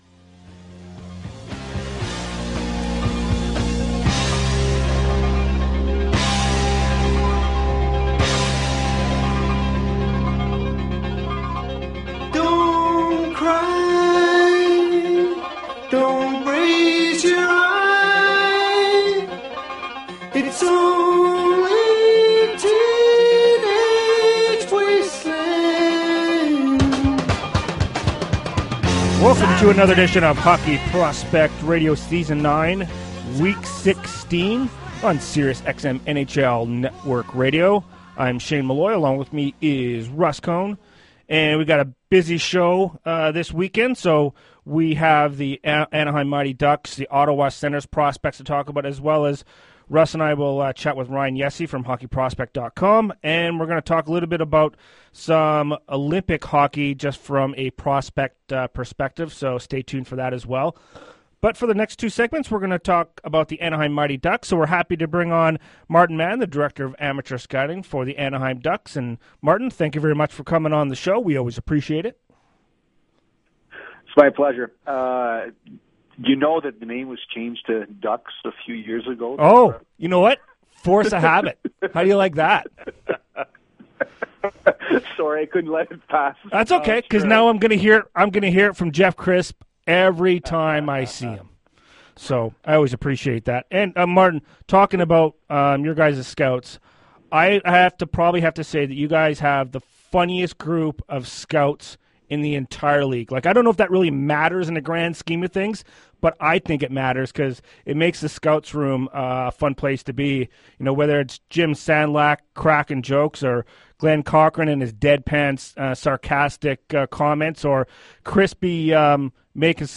on NHL Network radio’s Hockey Prospects show